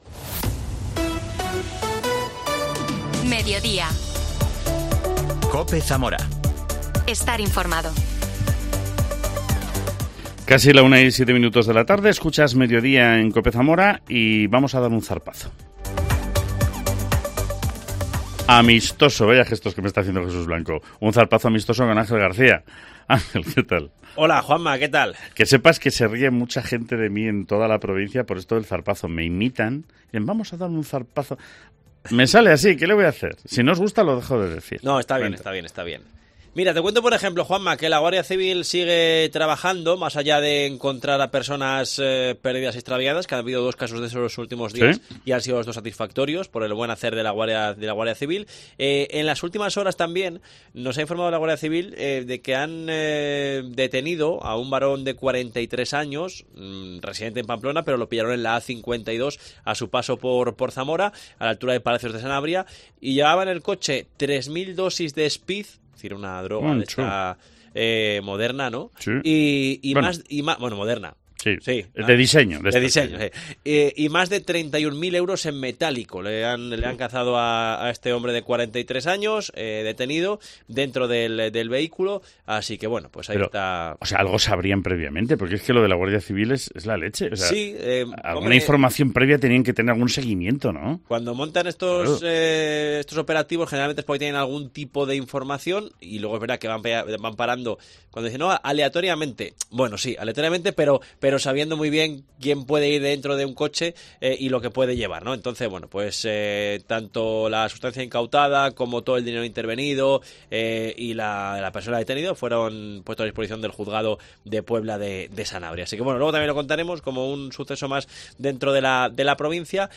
AUDIO: El alcalde de Toro, Tomás del Bien, habla sobre su expediente de expulsión del PSOE.